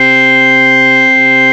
HAMMOND  2.wav